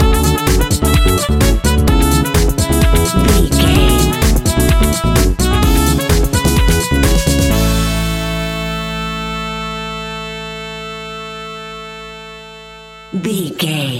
Ionian/Major
groovy
uplifting
bouncy
cheerful/happy
playful
electric guitar
horns
bass guitar
drums
nu disco
synth
upbeat
funky guitar
clavinet
synth bass